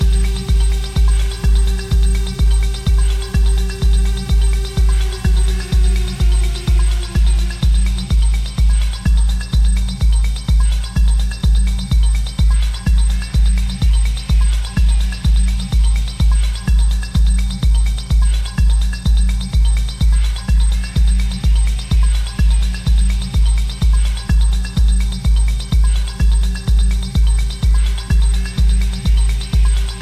Instrument électronique
Musiques électroniques - Techno